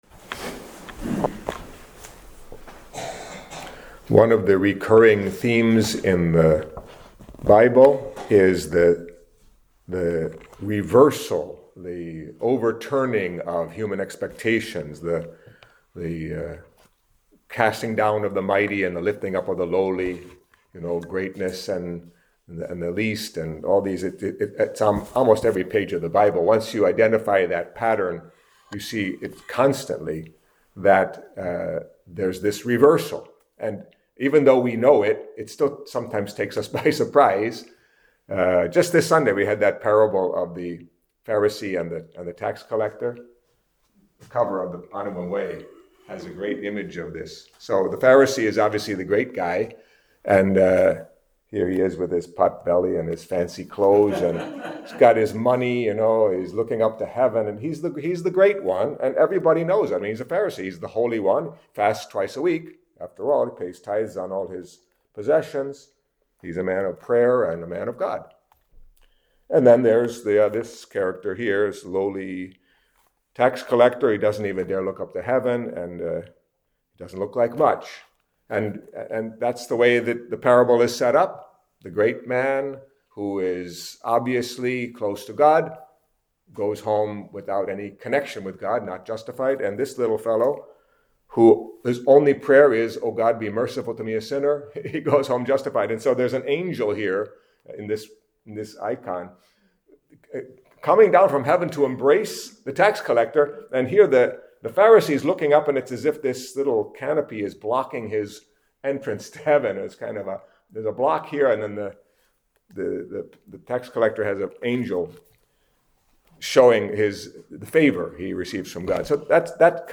Catholic Mass homily for the Feast of Saints Simon and Jude